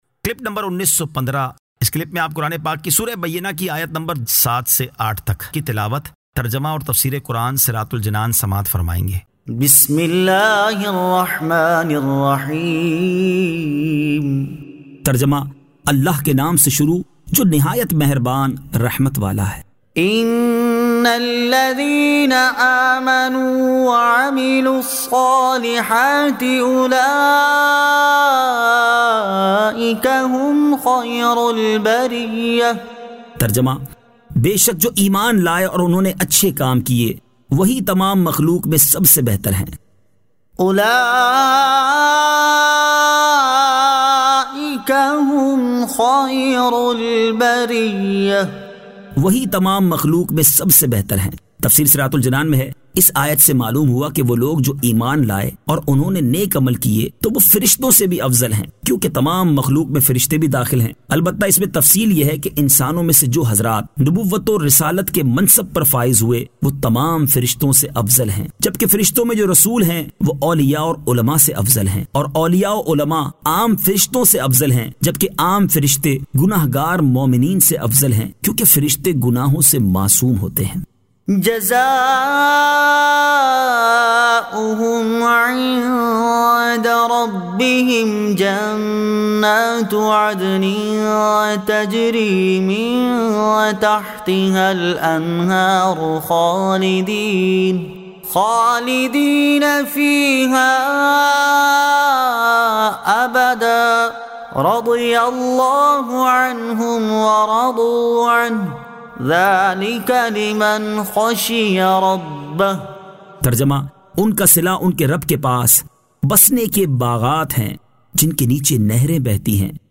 Surah Al-Bayyinah 07 To 08 Tilawat , Tarjama , Tafseer